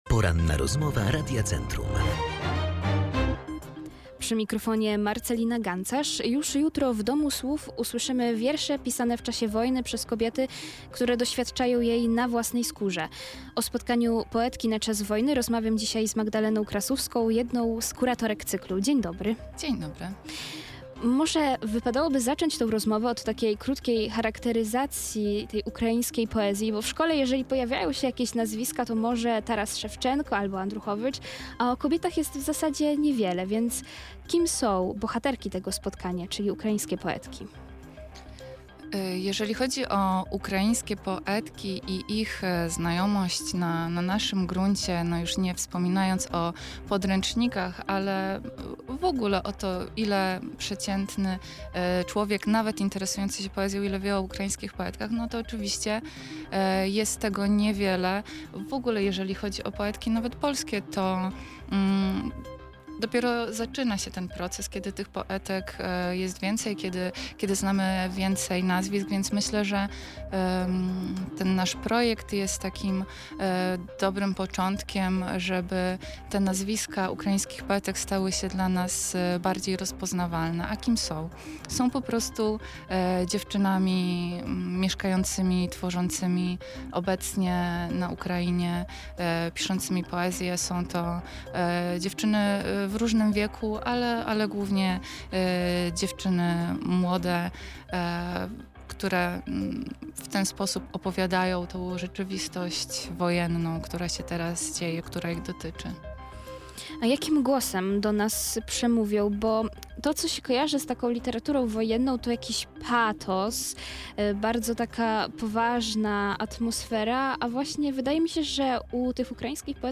Więcej na ten temat mogliśmy usłyszeć podczas Porannej Rozmowy Radia Centrum.
Poetki na czas wojny – cała rozmowa